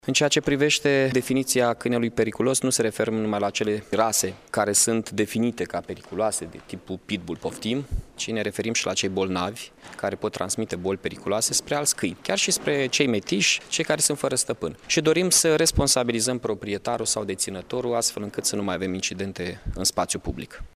Prin definiţie, câinii periculoşi sunt cei din rasele catalogate ca fiind periculoase, câinii antrenaţi pentru luptă şi cei care sunt purtători ai unor boli periculoase, a mai spus Mihai Chirica: